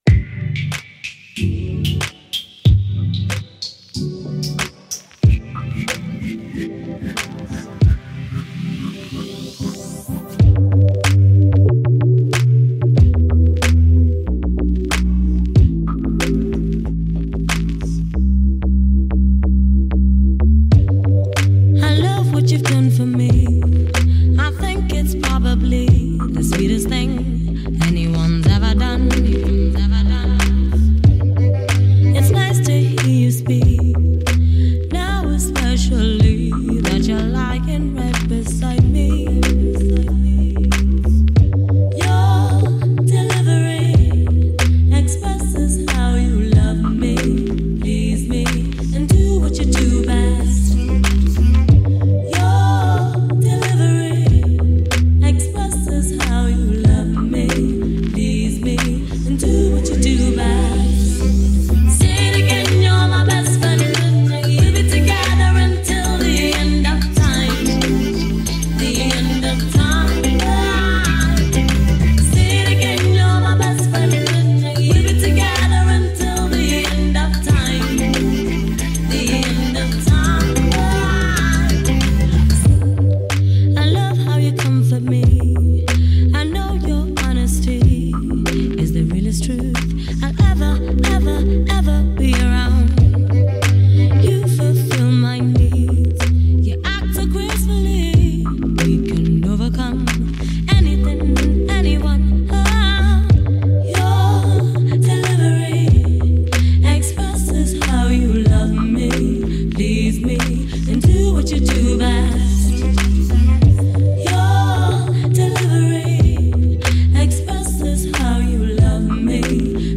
dark, electronic arrangements for a mesmerizing sound